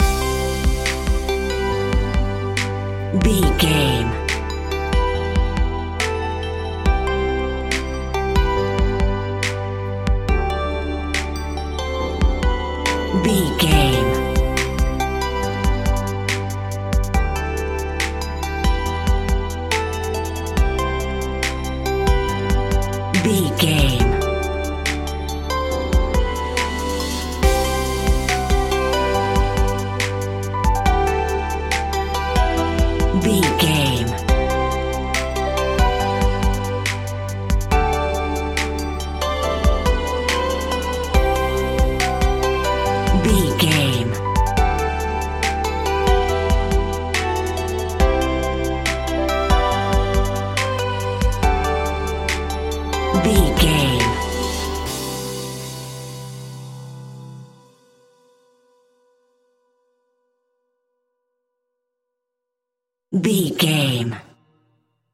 Ionian/Major
Slow
instrumentals
chilled
laid back
groove
hip hop drums
hip hop synths
piano
hip hop pads